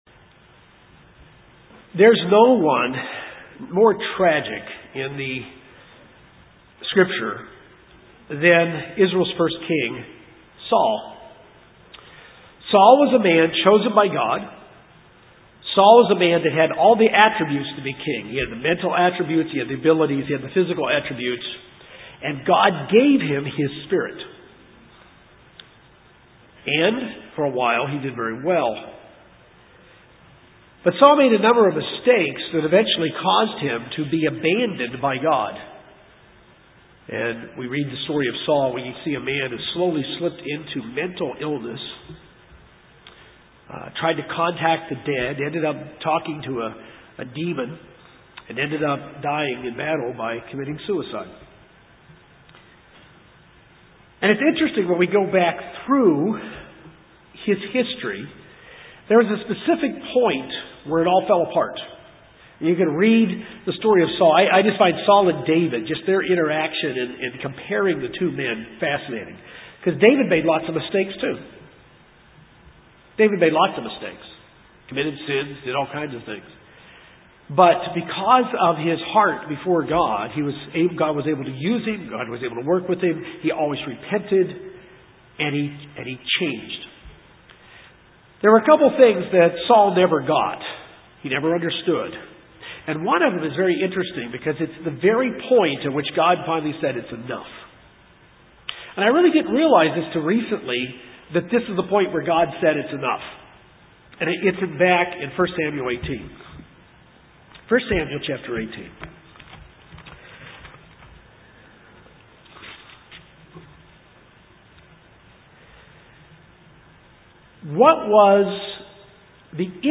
There is an emotion that we as human beings have to understand and deal with or it can take us out of acceptance by God the Father. This sermon looks at three causes of resentment to help us to understand how serious resentment is.